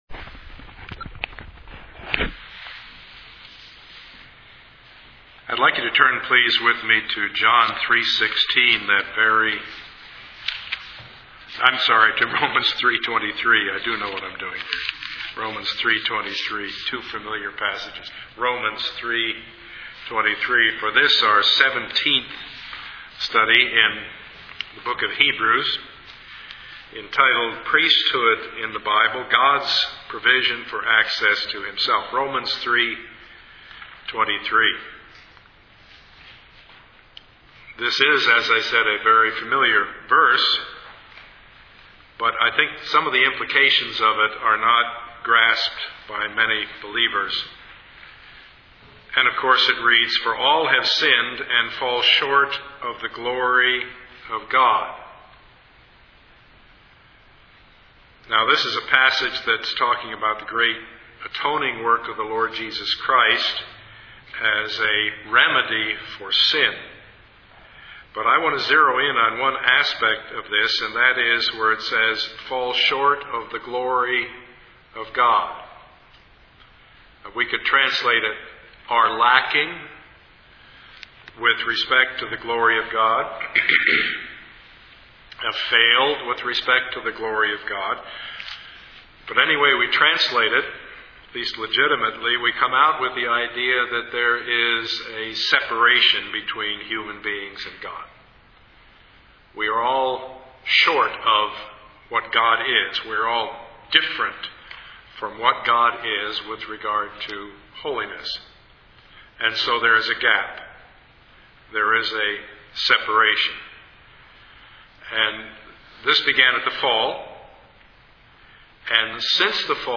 Review of Part 17 of the Sermon Series